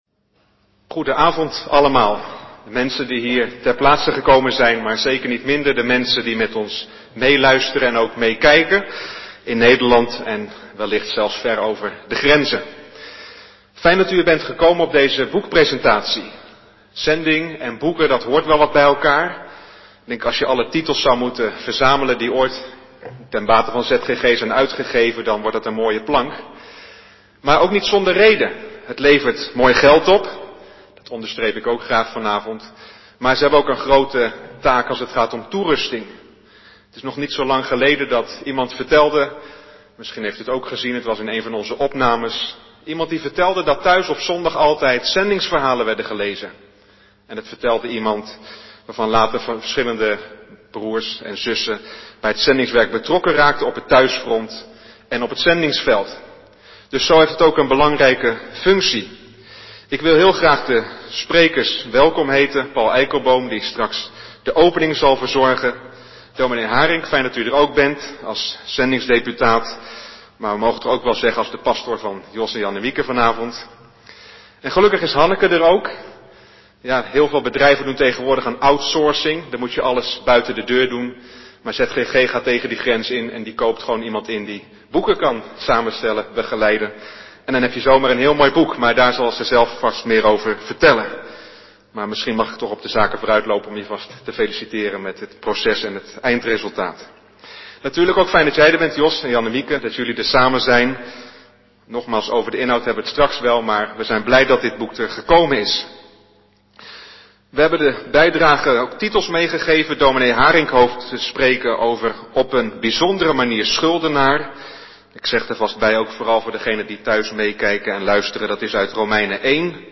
De boekpresentatie van 'Omgekeerd' op vrijdagavond 25 september is via een geluidsopname terug te luisteren.
Naluisteren boekpresentatie 'Omgekeerd'
Het inhoudelijk zeer gevarieerde programma werd door een aantal genodigden bijgewoond.
Helaas was de beeldkwaliteit door internetproblemen niet al te best, maar het geluid was goed.